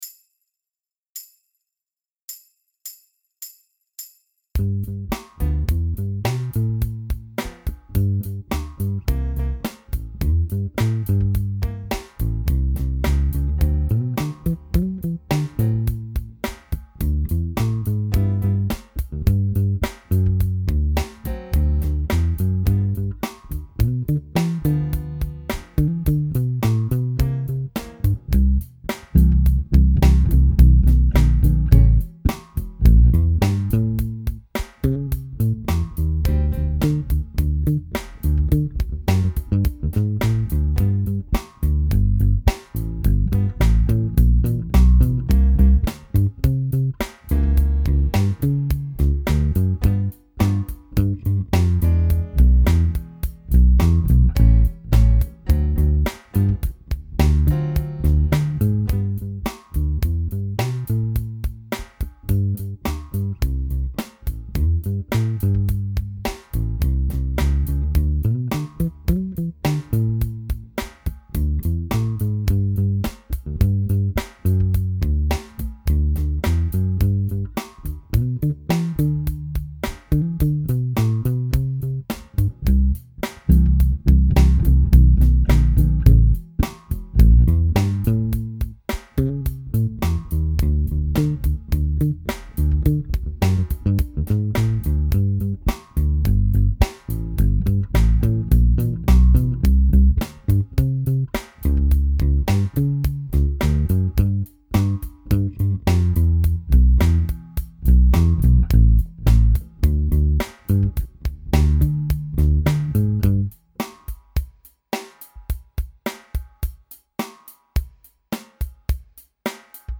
notated bass lines in the classic styles
29 R&B - .mp3